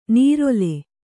♪ nīrole